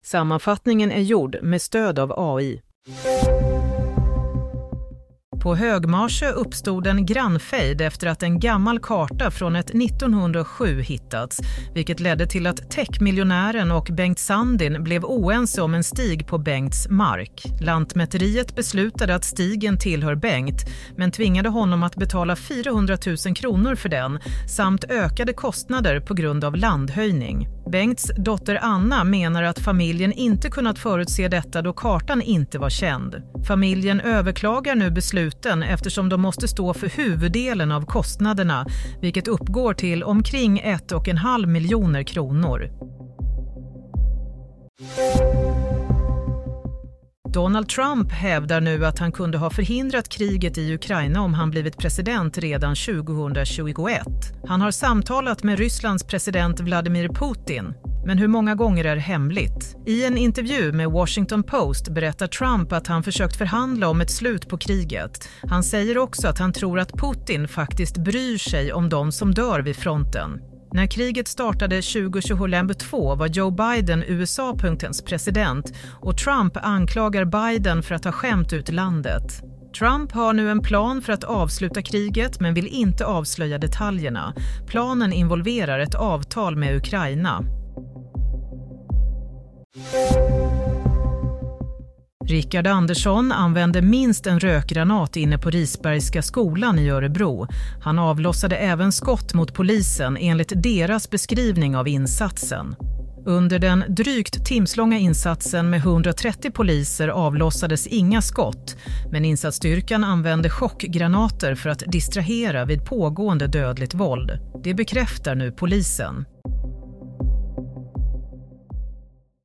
Nyhetssammanfattning - 9 oktober 15:35